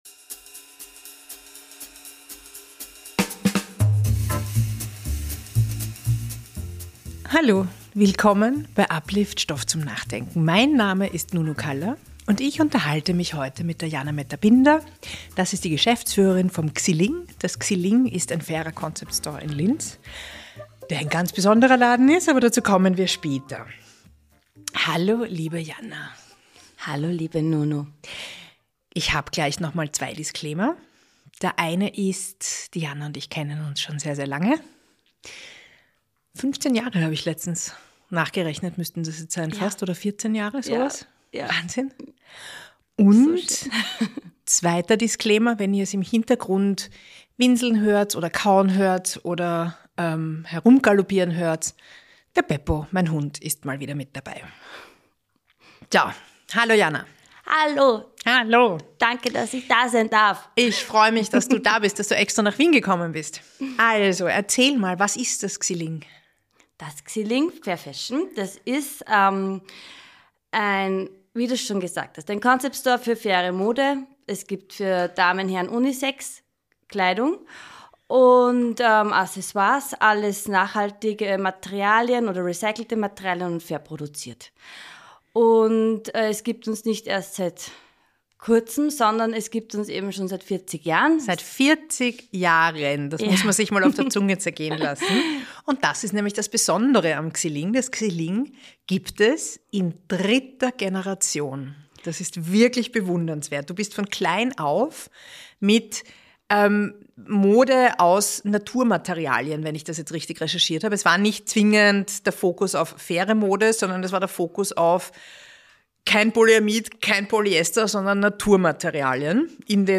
Was für ein schönes Gespräch! Uplift – Stoff zum Nachdenken möchte inspirieren.